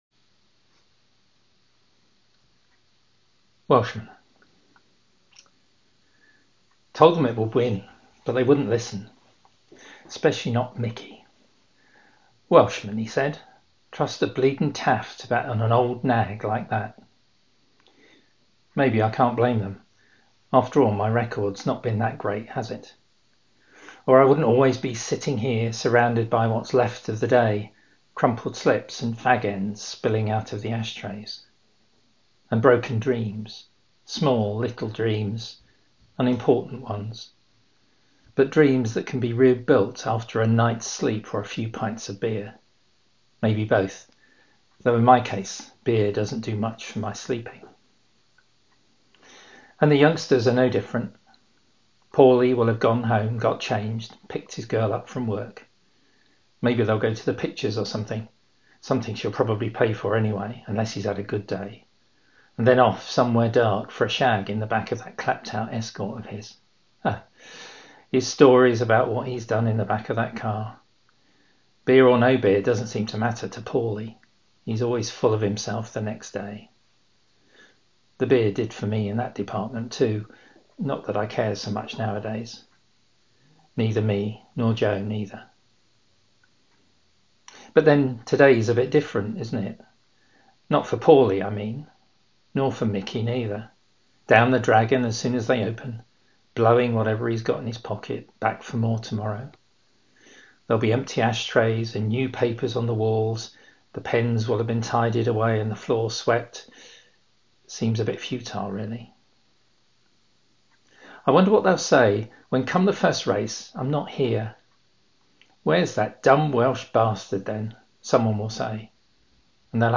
Welshman – a reading